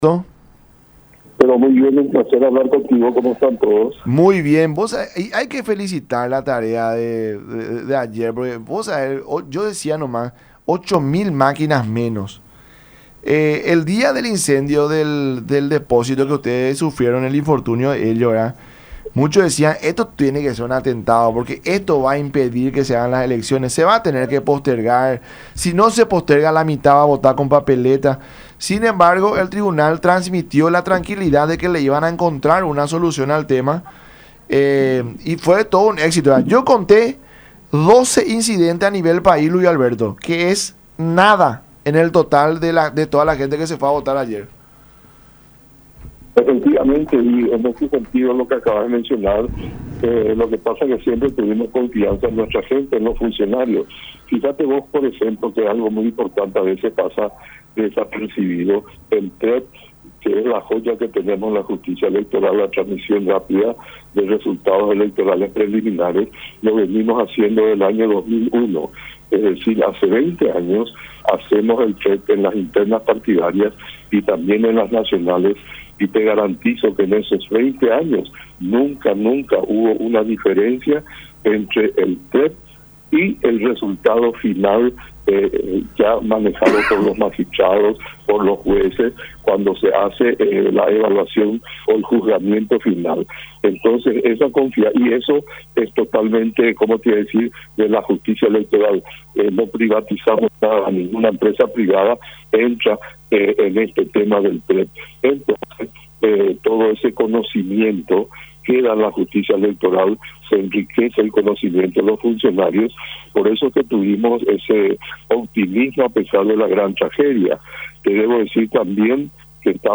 en diálogo con La Mañana De Unión a través de Unión TV y radio La Unión